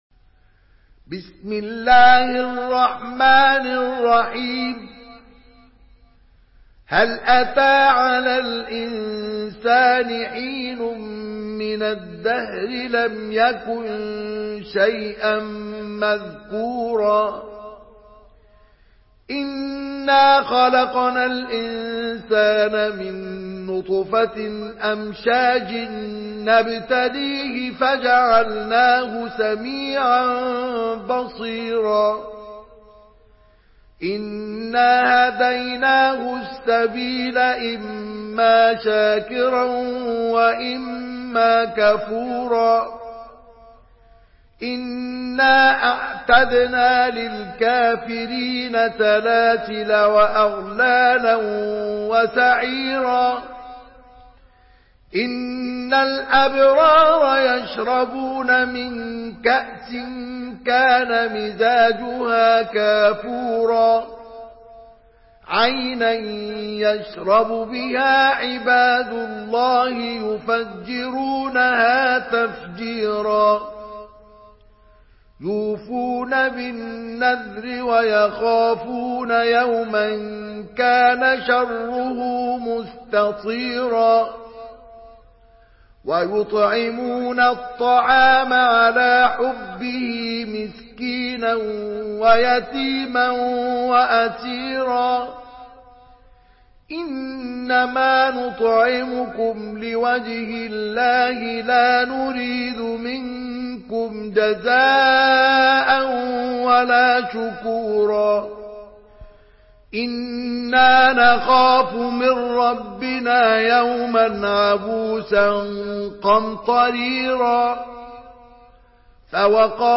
Surah Insan MP3 by Mustafa Ismail in Hafs An Asim narration.
Murattal Hafs An Asim